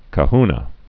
(kə-hnə)